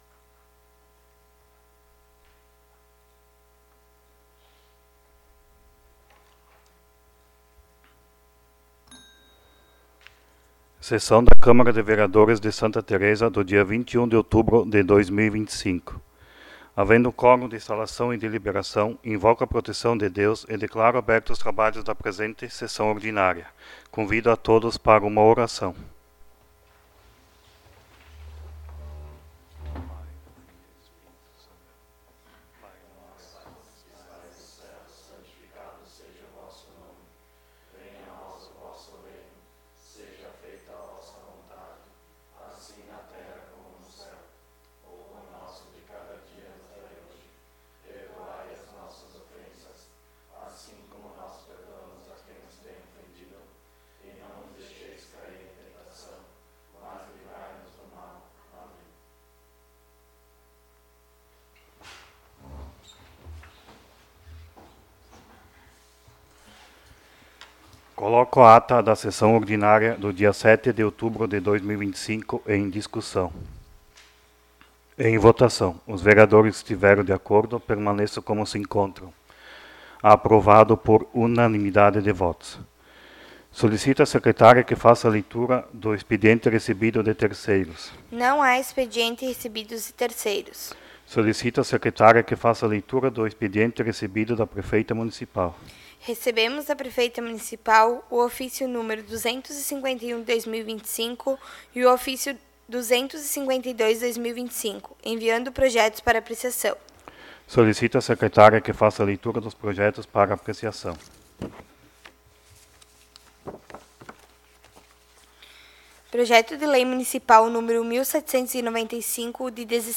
18° Sessão Ordinária de 2025